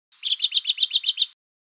Listen to the singing of three Darwin´s finch species:
Small Tree Finch
Song_Small_Tree_Finch.mp3